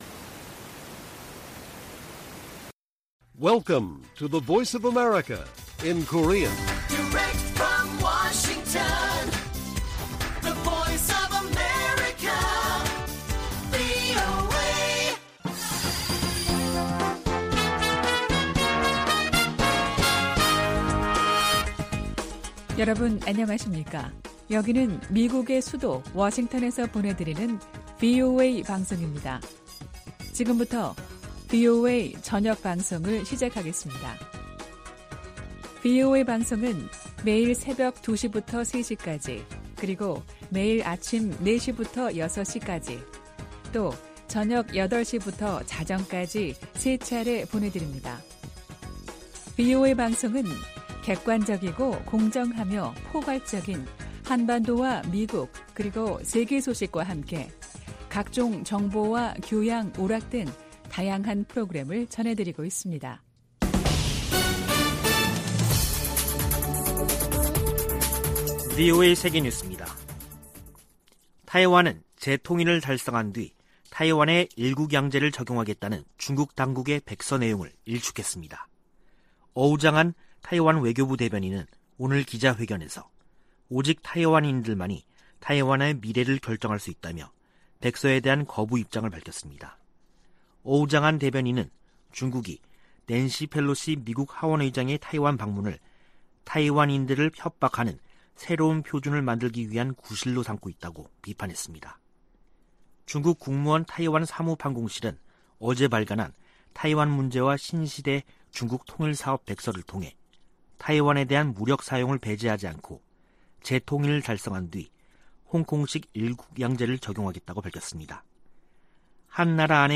VOA 한국어 간판 뉴스 프로그램 '뉴스 투데이', 2022년 8월 11일 1부 방송입니다. 미 국무부는 중국의 사드와 관련한 한국에 대한 이른바 3불1한 주장은 부적절하다고 지적했습니다. 한국 대통령실 측은 사드는 북한 핵과 미사일로부터 국민을 지키기 위한 자위 방어 수단이라며 협의 대상이 결코 아니라고 강조했습니다. 북한의 거듭된 탄도미사일 발사가 한반도의 긴장을 고조시키고 있다고 아세안지역안보포럼 외교장관들이 지적했습니다.